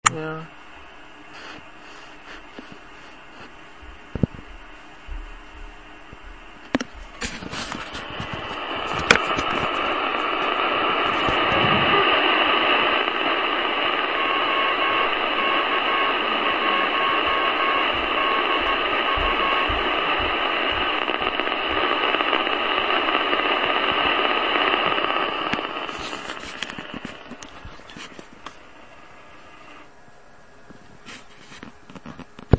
To samozřejmě jde taky, ale blbě budete hledat ten správný zázněj, bude jich tam více a bude Vám překážet nosná.